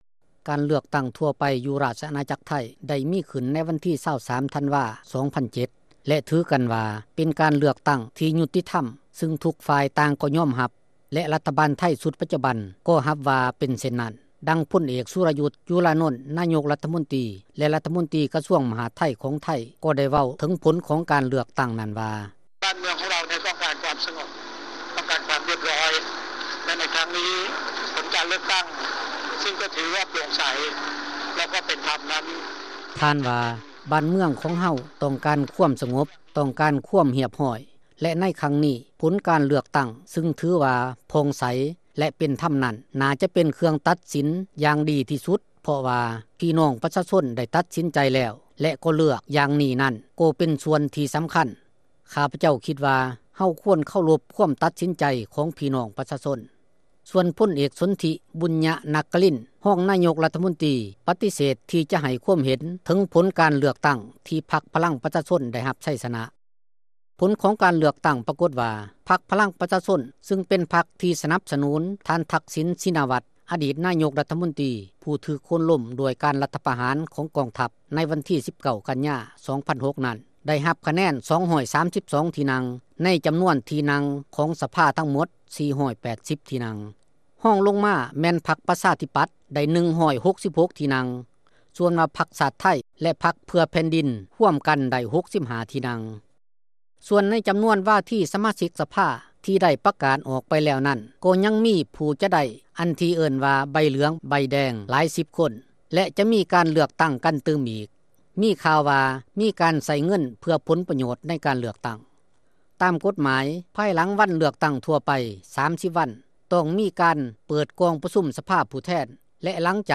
ບົດວິເຄາະ